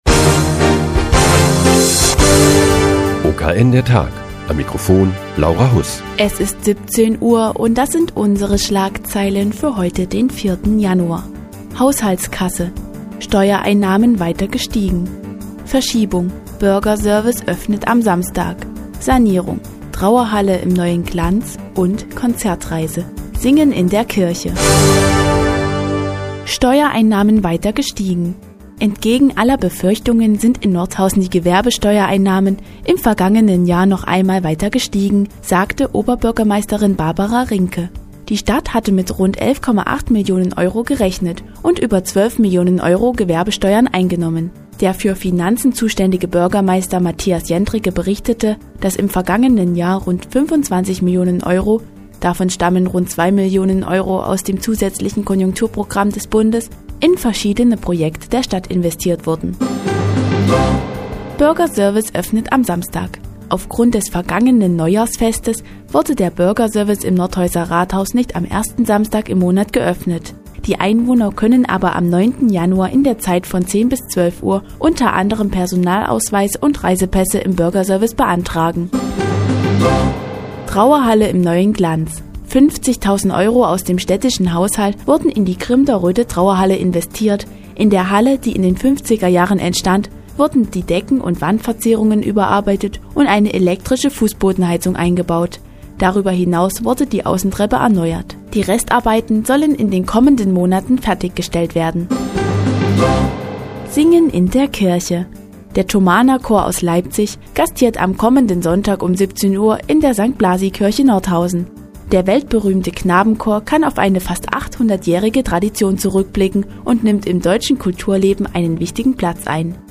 Die tägliche Nachrichtensendung des OKN ist nun auch in der nnz zu hören. Heute geht es um die Gewerbesteuereinnahmen im Jahr 2009 und den Auftritt des Leipziger Thomanerchores in Nordhausen.